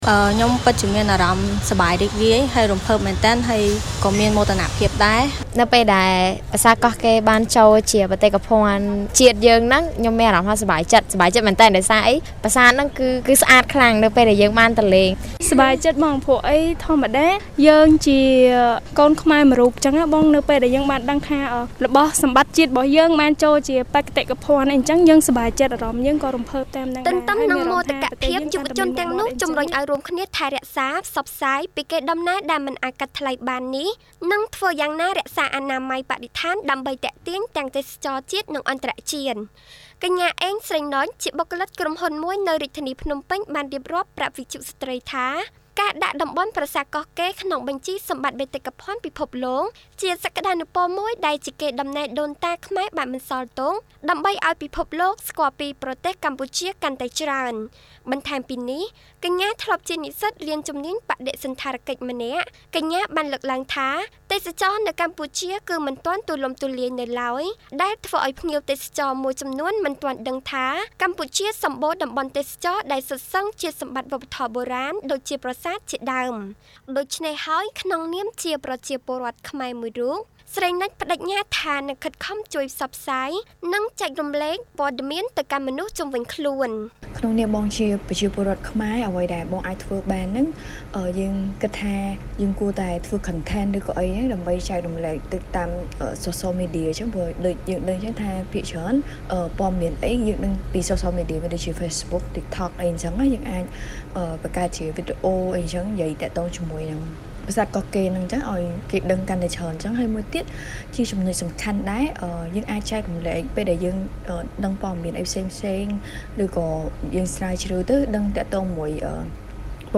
សូមនាងកញ្ញា លោក លោកស្រី ស្ដាប់នូវបទយកការណ៍